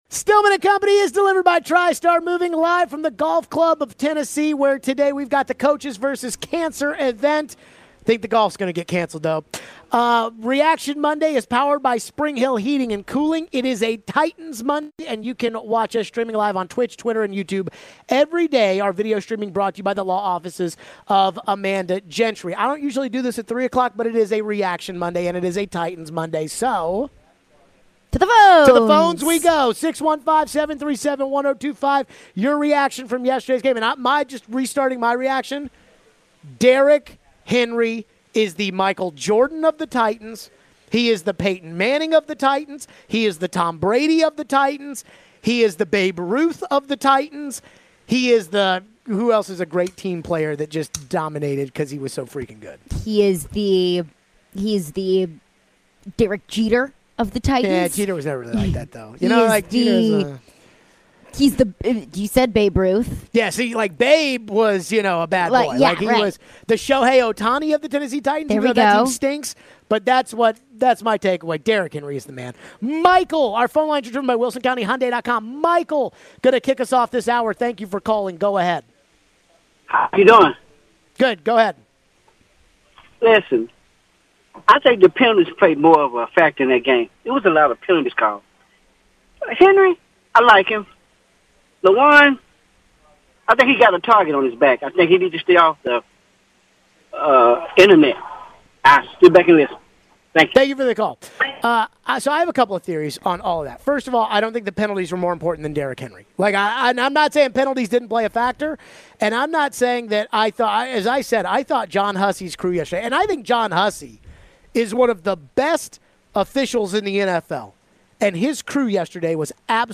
We go back to your phones on the Titans win over Seattle. Do we think some of the players are on social media too much?
What did we think of Julio Jones and his bounce-back game? Plus more phones.